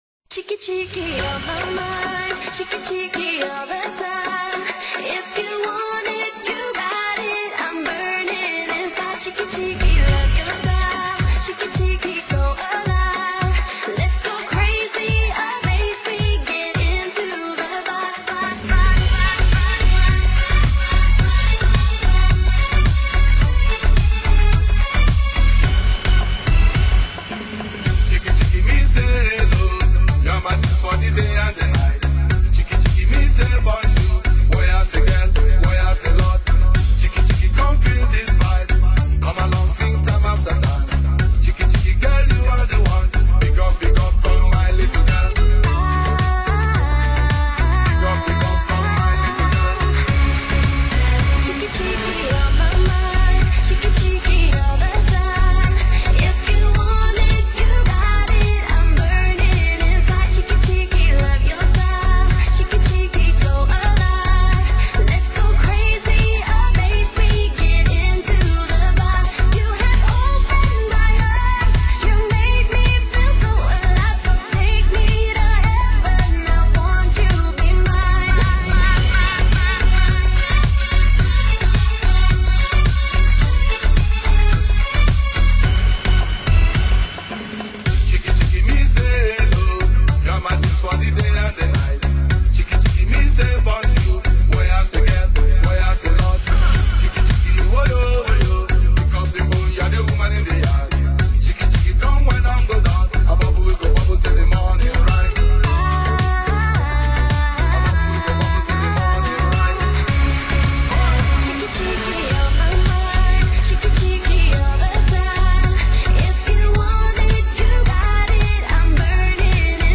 慢嗨舞曲
【嗨客舞曲】
最新外文慢嗨沖高潮舞曲